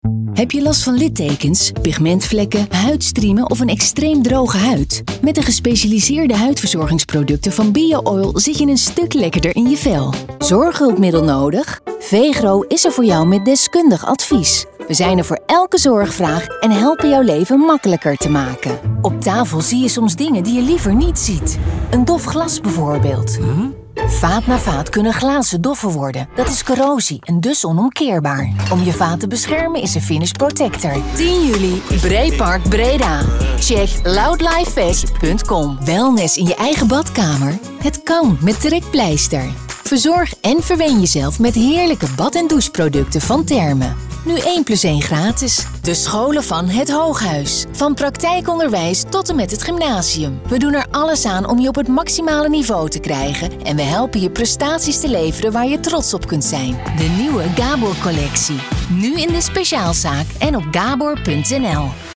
Commercial, Reliable, Friendly, Warm, Corporate
Commercial
Besides a friendly and warm voice, she can also sound commercial, cheerful, reliable, businesslike, open, informative, fresh, recognizable, sultry and clear.